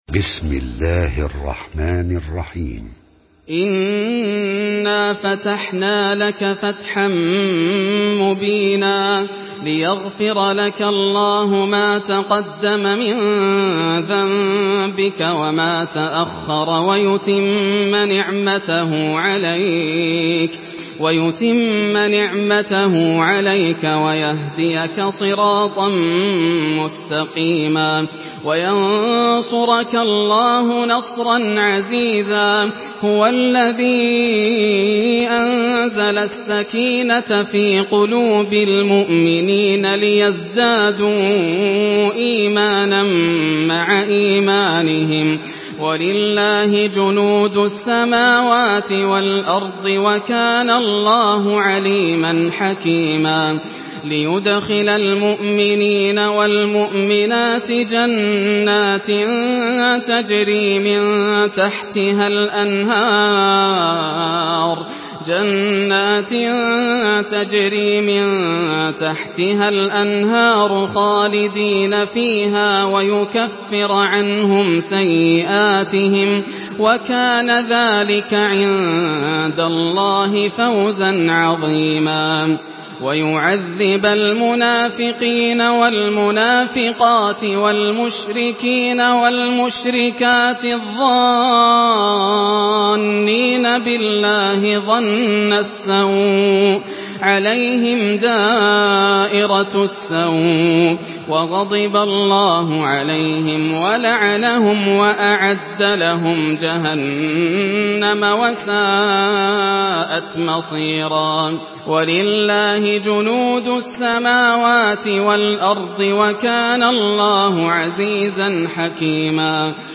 Surah আল-ফাতহ MP3 by Yasser Al Dosari in Hafs An Asim narration.
Murattal Hafs An Asim